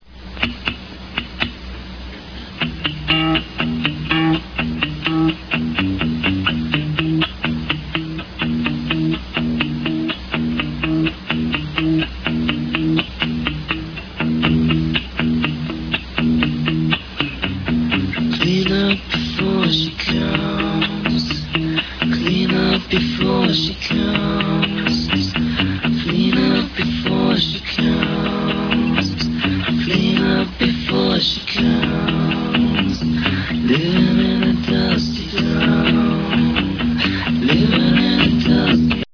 The latter has some cool 4-track effects
uses dual layers to get two mixes of his voice on each other